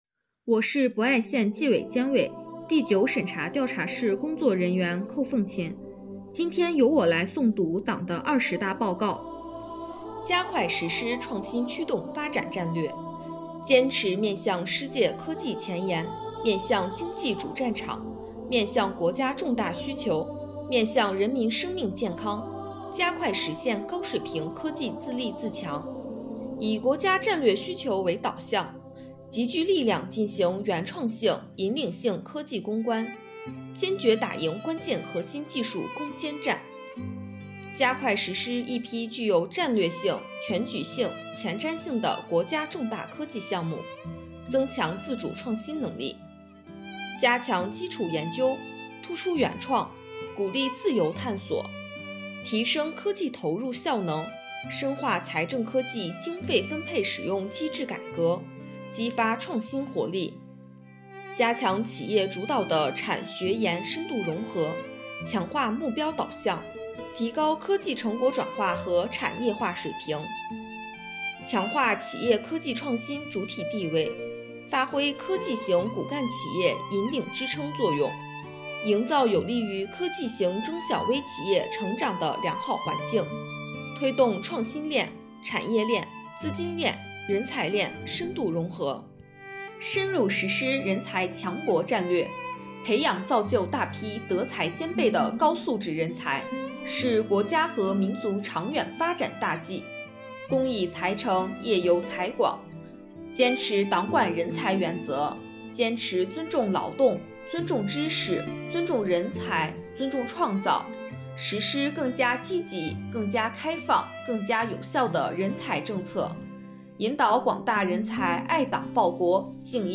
本期诵读人
诵读内容